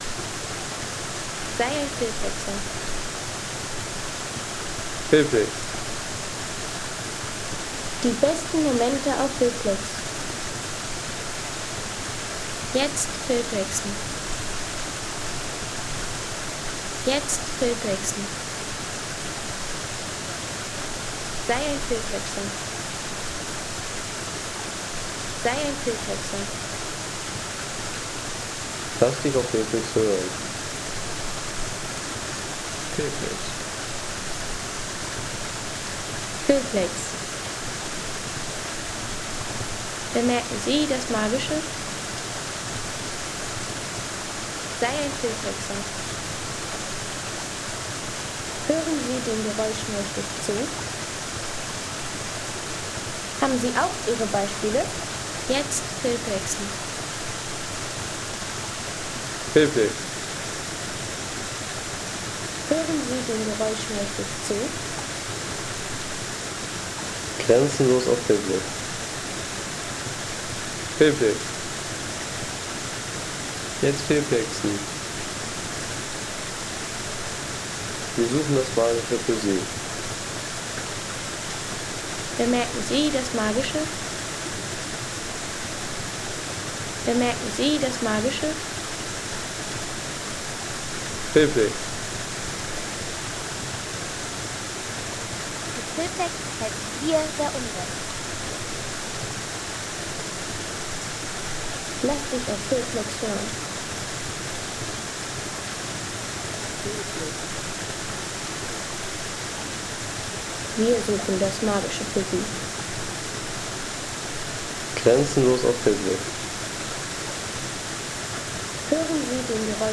Val Grande Wasserfall Sound | Feelplex
Ein Wasserfall-Sound tief aus der Wildnis
Wilde Wasserfall-Atmosphäre aus dem Nationalpark Val Grande mit Schluchtkulisse und unberührter Natur.
Natürliche Wasserfallkulisse aus dem Val Grande mit Schluchtcharakter und unberührter Wildnisatmosphäre für Film und stimmungsvolle Projekte.